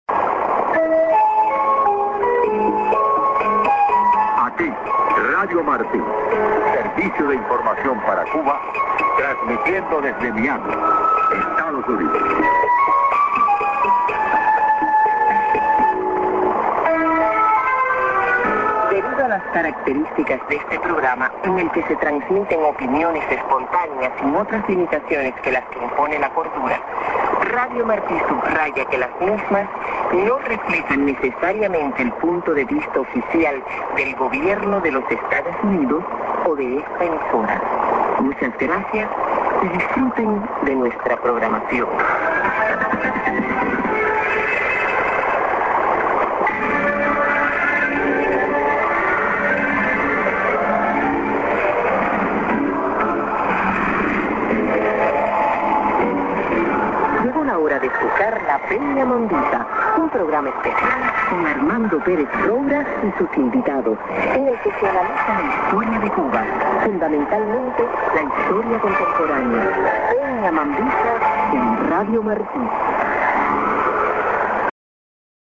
->ST+ID(man)->ST->ID(women)->